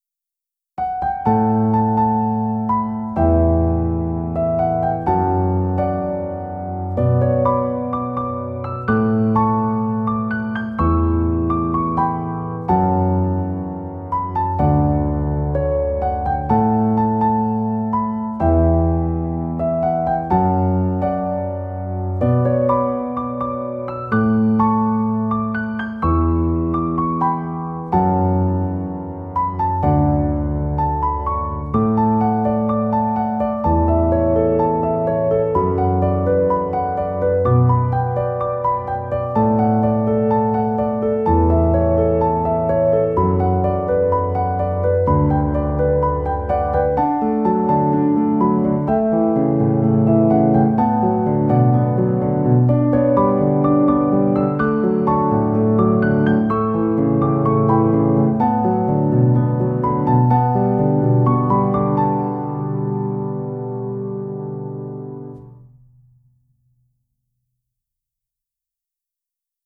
music / PIANO D-G